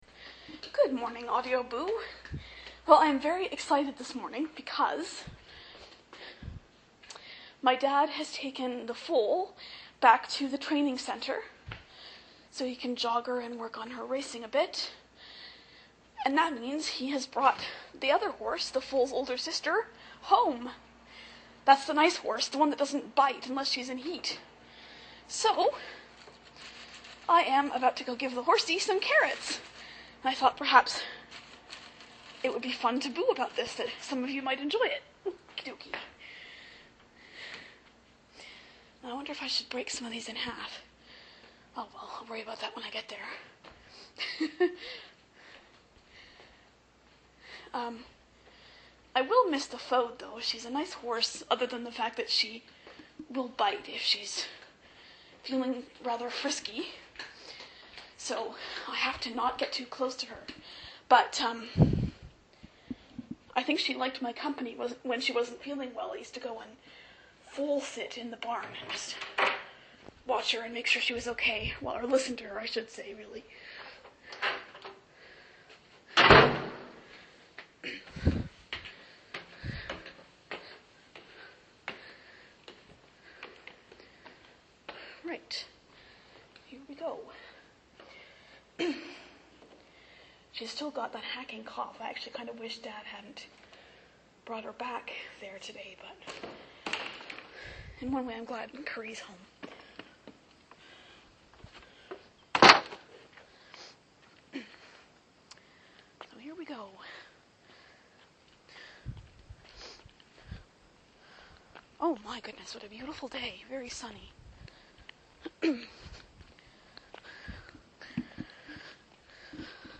Sorry for the rustly microphone noise; I had to put the recorder in my pocket.